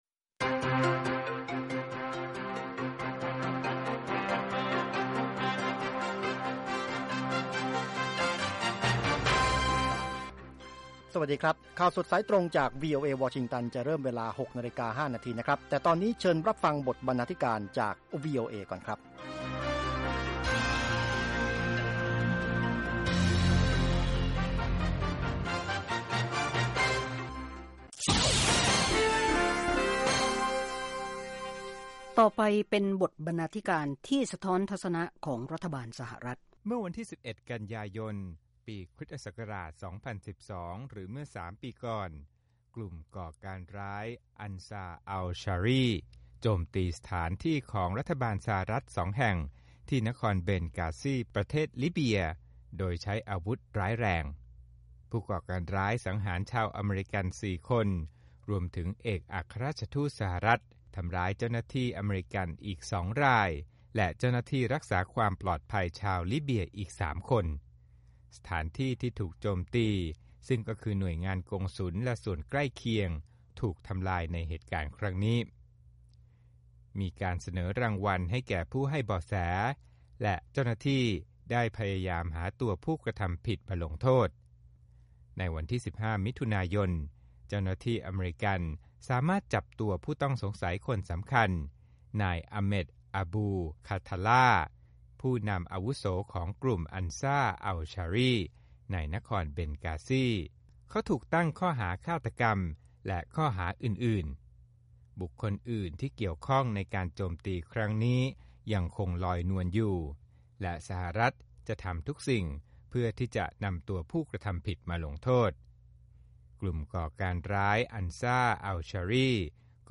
ข่าวสดสายตรงจากวีโอเอ ภาคภาษาไทย 6:00 – 6:30 น. ศุกร์ ที่ 25 กันยายน 2558